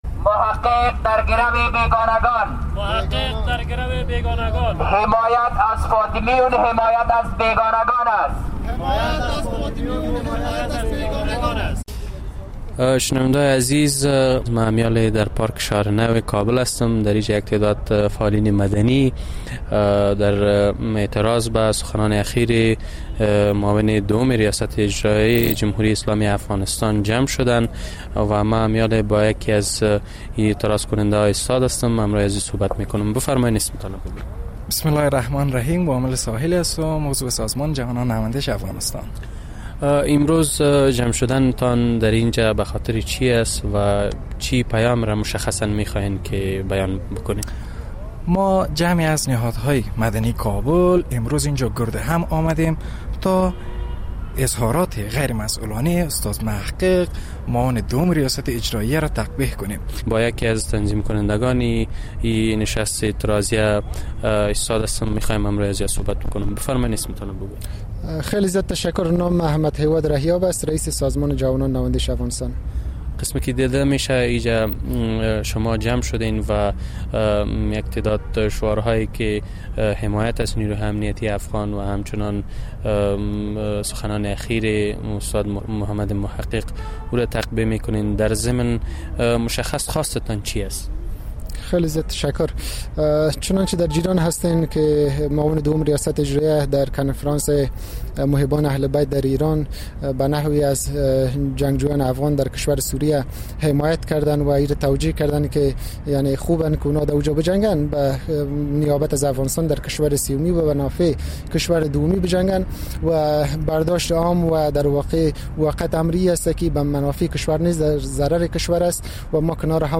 این معترضان که شمار شان به ده‌ها تن می‌رسید با سر دادن شعارها، صبح امروز در پارک شهر نو کابل گردهم آمدند و با نکوهش سخنان معاون دوم ریاست اجرائیه آن‌را غیر مسئولانه خواندند.